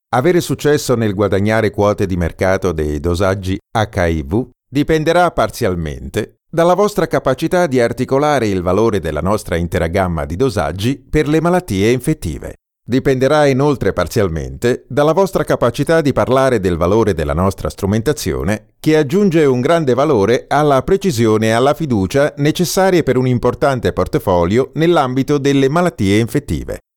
Italian Speaker, Italian Voice over talent, middle/old voice
Sprechprobe: eLearning (Muttersprache):
My voice has a low tone from warm tone and is suitable for commercials institutional advertising and so on, answering machines, documentaries, jingles, audio books, audio guidance, e-learning, voice over, multimedia audio voiceovers